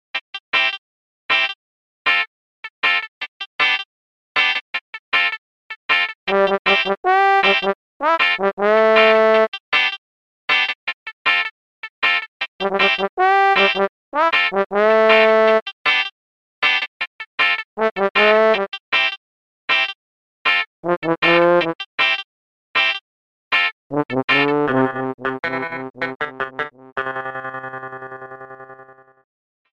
Trimmed, added fadeout
Fair use music sample